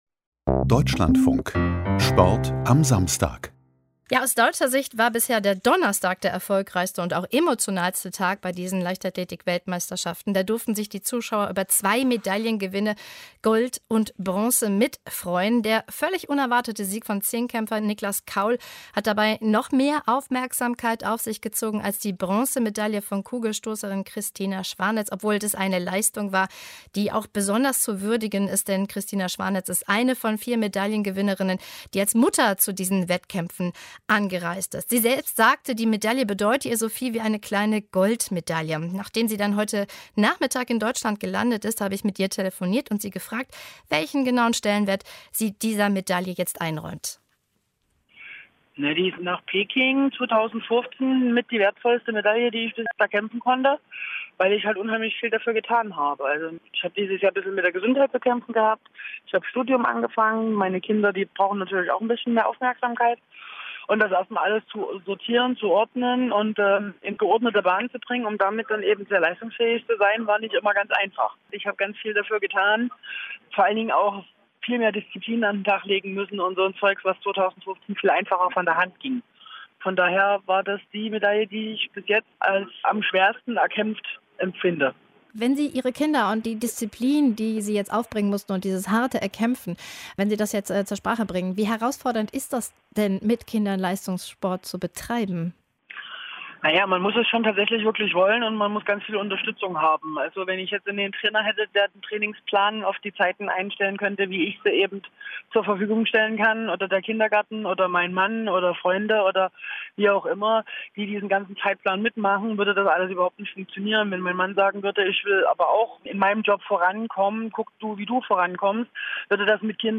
Christina Schwanitz in Gespräch